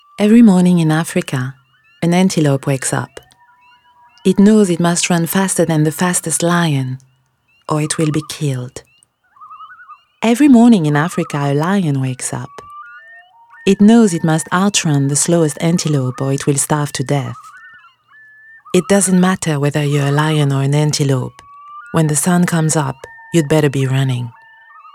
Ana dil spikerleri